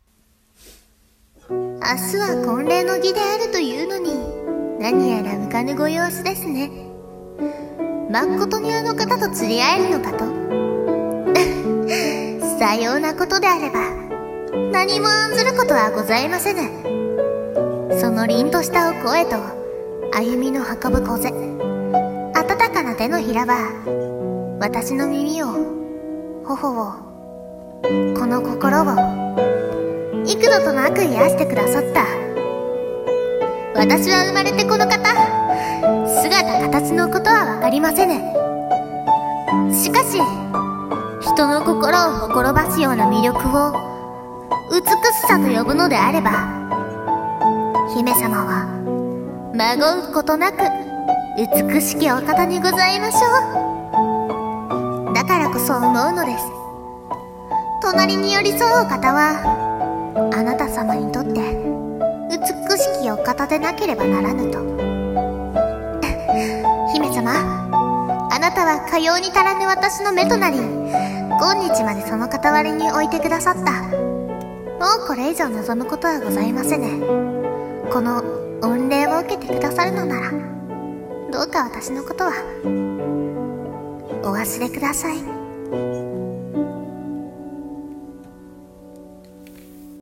【和風台本】美しき、【一人声劇】